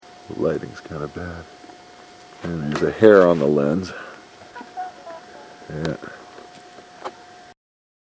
For the most part there was nothing unusual at all, just natural background sounds and our voices.
(Go back to the laugh in the cemetery to compare--that's a sound from much farther away--notice how dull it sounds?)
cemetery.mp3